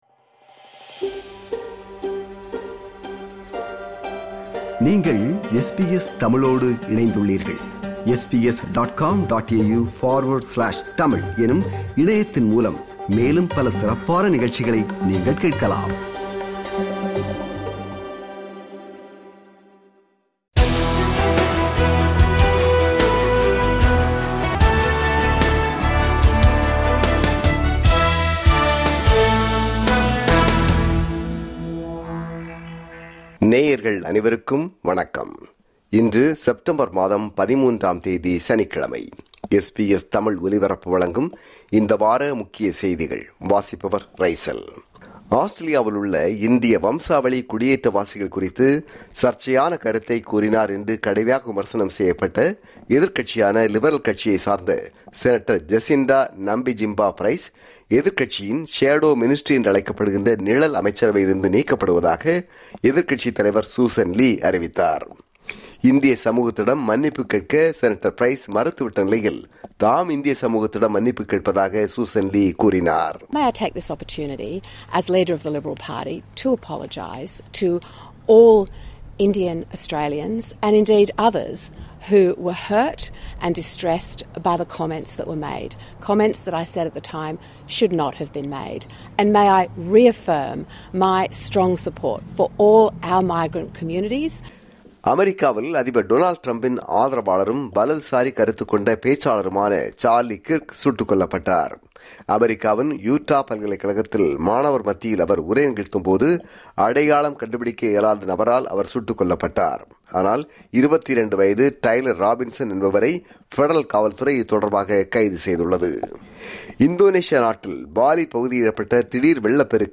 SBS Studio Source: SBS / SBS Radio